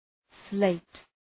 Προφορά
{sleıt}